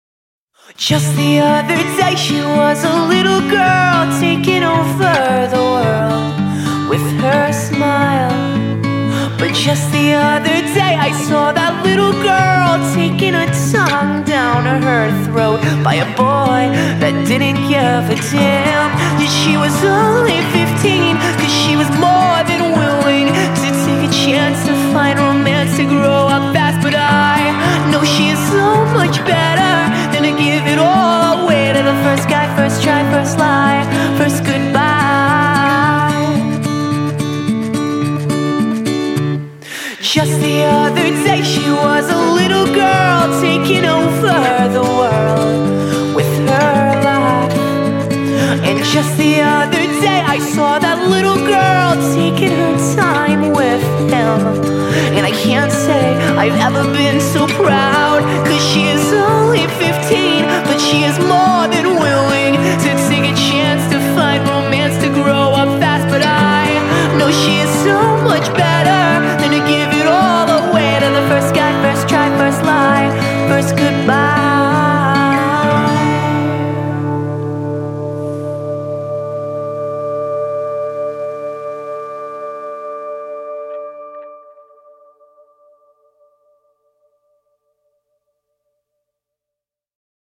knack for melodic hooks